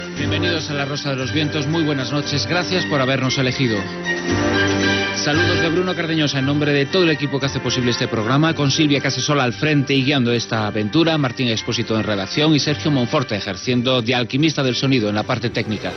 Inici del programa i crèdits.